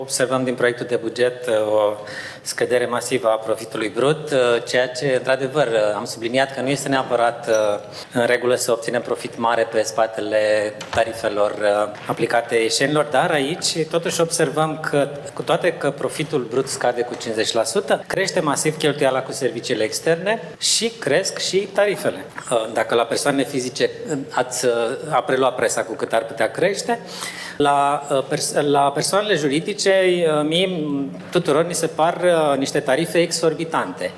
Ieșenii urmează să plătească cu aproximativ 10% mai mult pentru salubritate, după ce tarifele majorate cu rata inflației pentru serviciile prestate de Salubris au primit undă verde în ședința de astăzi a Consiliului Local.